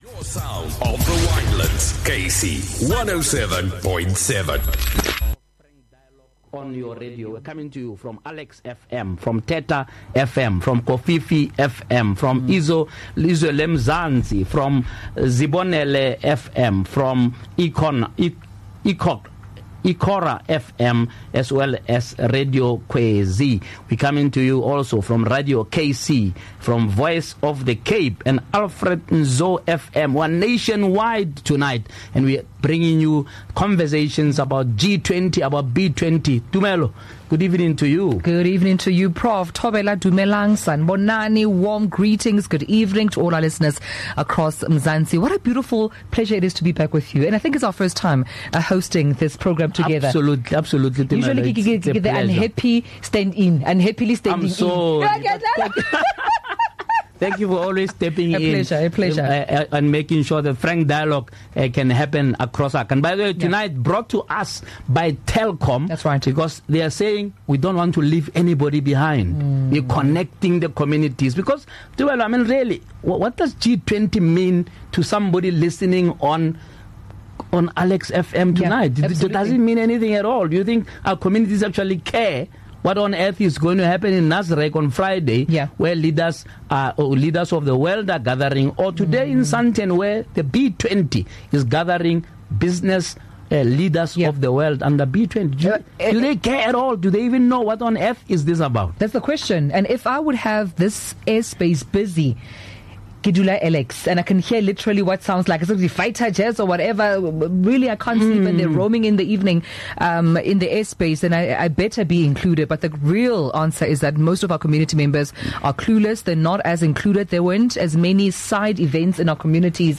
Together, we bring you a special broadcast series designed to unpack the key outcomes of the G20 Summit and explore what they mean for South Africa and the African continent.
This powerful one-hour syndication programme will bring clarity, insight, and compelling discussion on global decisions shaping our local reality.